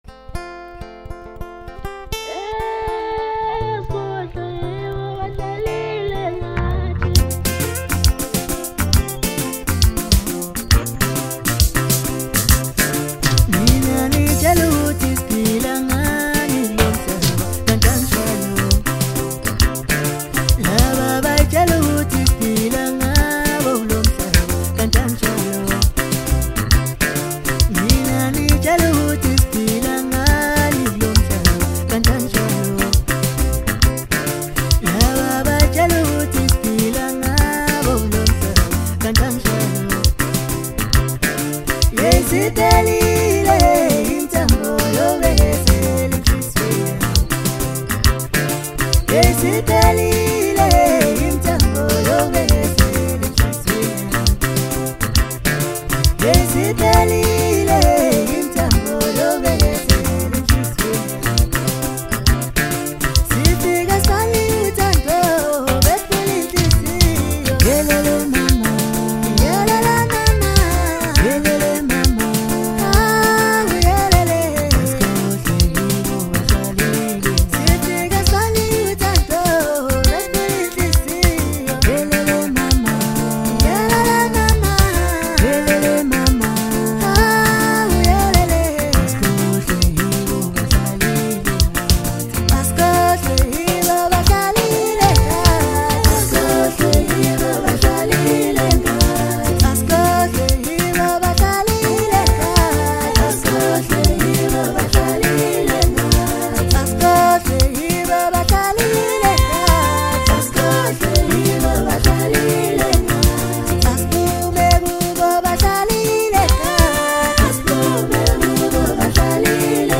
Home » Hip Hop » Maskandi
Talented vocalist
catchy rhythm, smooth vibe, and strong replay appeal